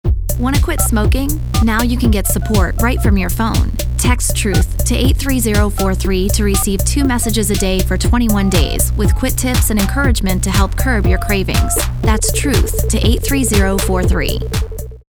Also, attached are radio station produced advertisements for our program: